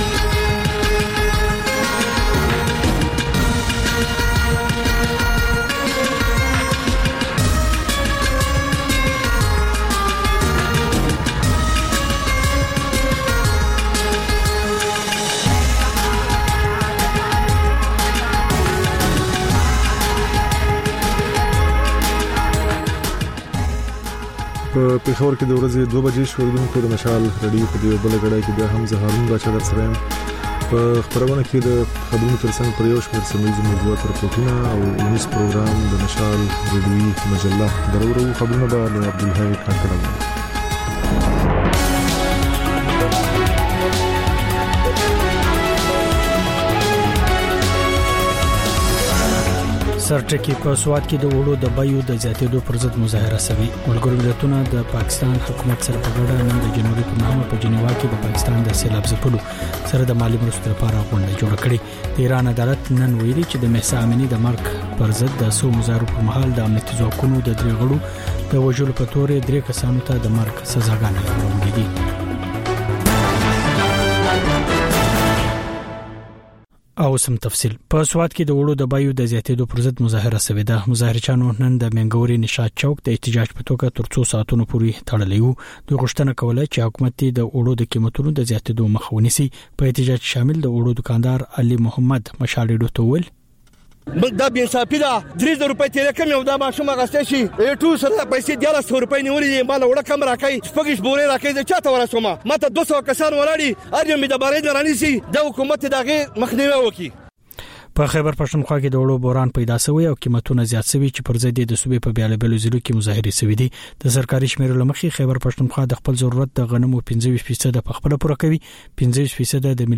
د مشال راډیو دویمه ماسپښینۍ خپرونه. په دې خپرونه کې تر خبرونو وروسته بېلا بېل رپورټونه، شننې، مرکې خپرېږي.